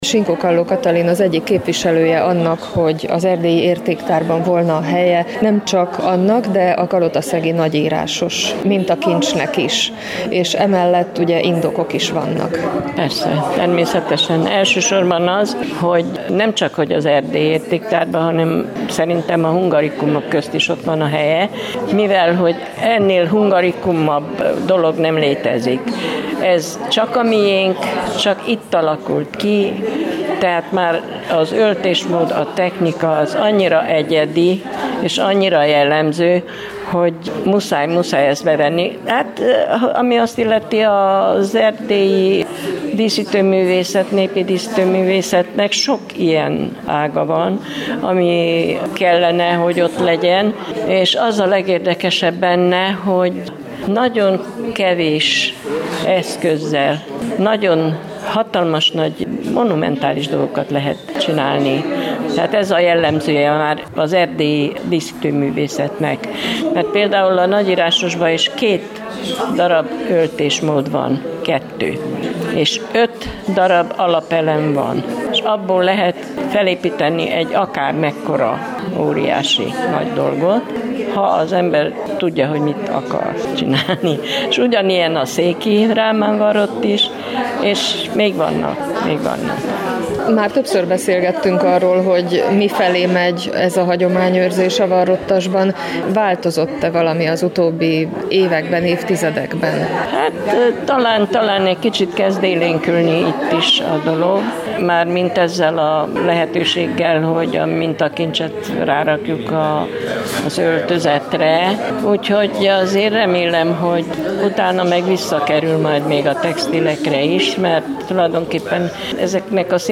Mintákról, motívumokról, a nagyírásos történetéről is beszéltek a témában szervezett kolozsvári találkozón.